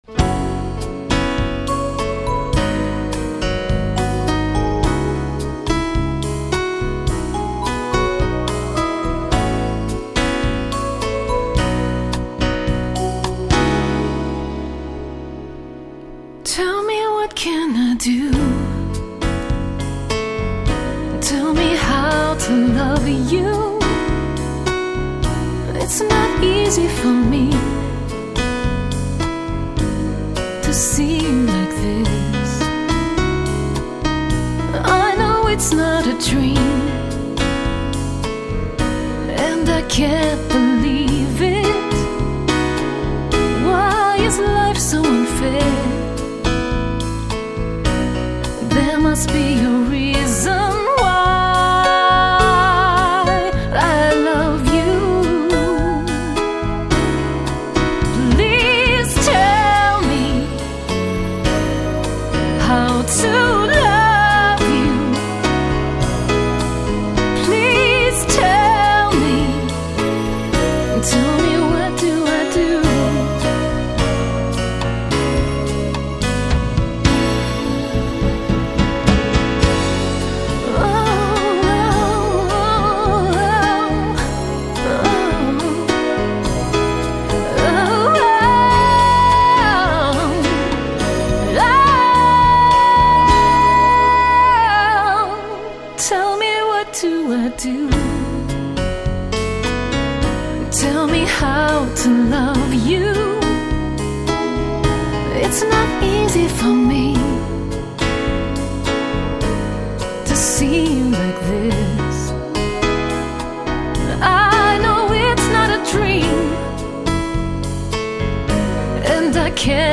vocals
sax
drums